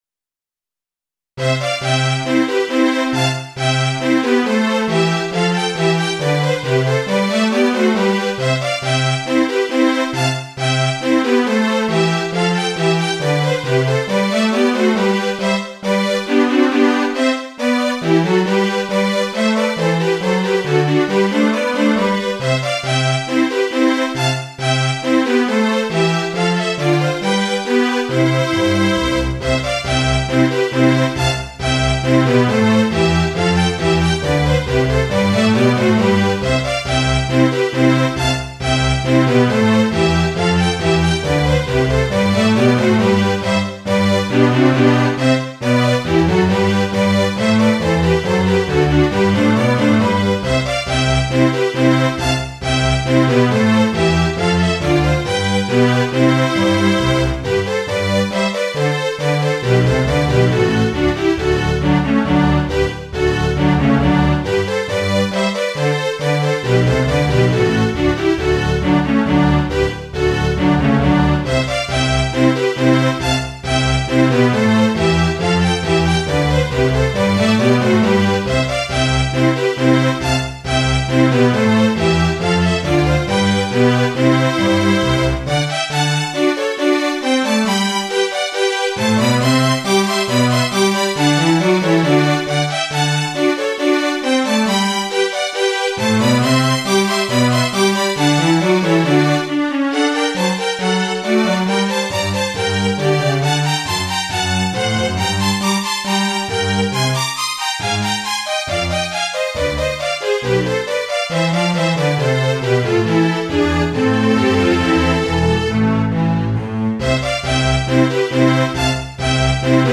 This piece is for strings only.